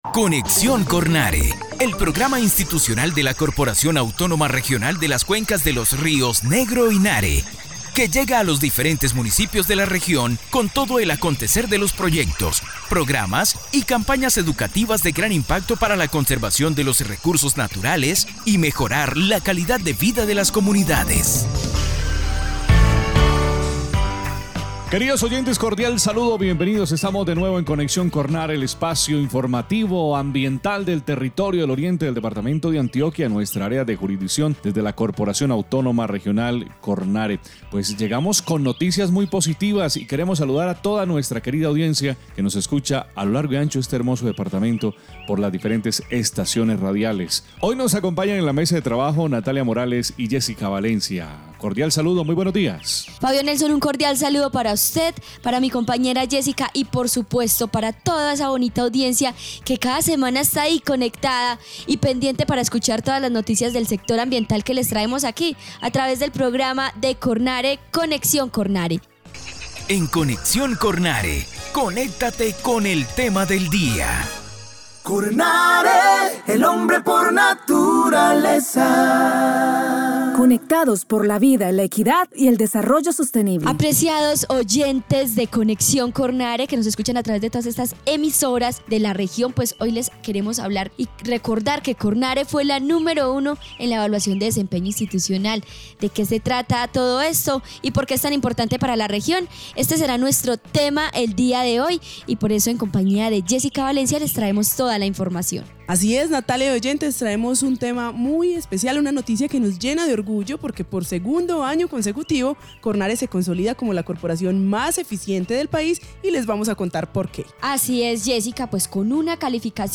Programa de radio 2022